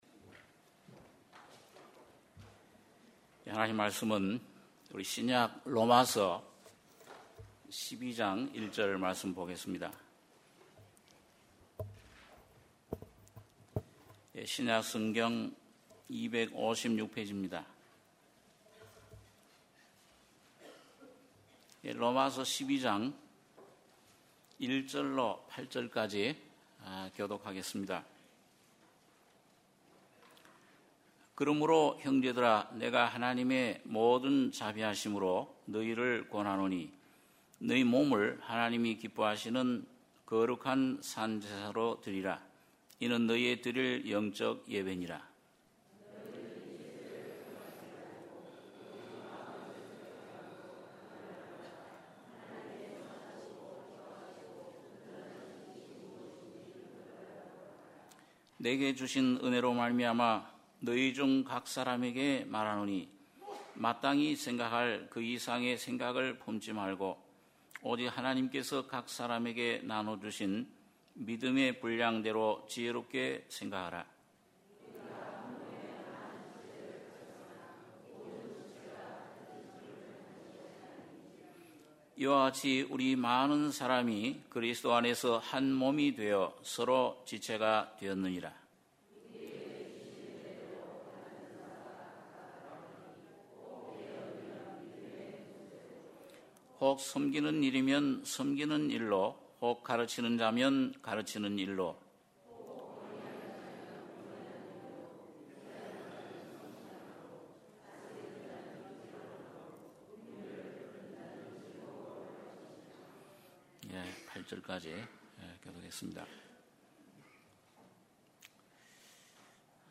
주일예배 - 로마서 12장 1절-8절